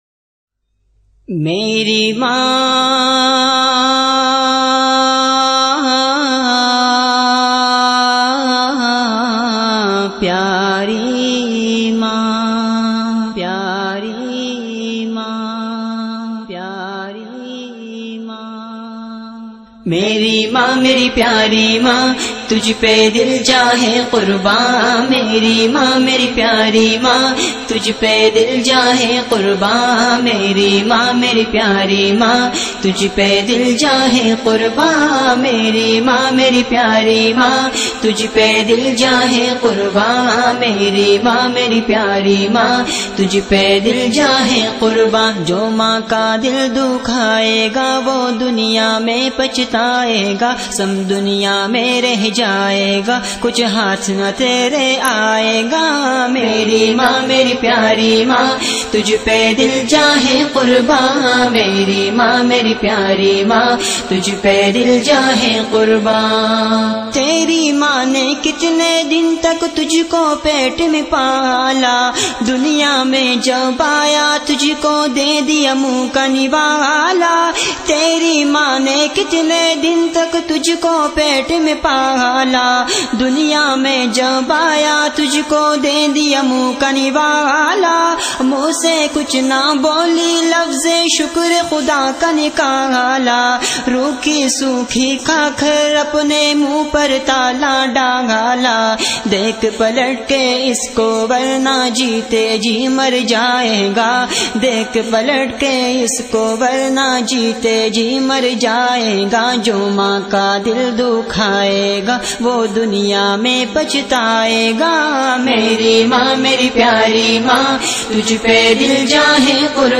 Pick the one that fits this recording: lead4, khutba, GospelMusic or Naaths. Naaths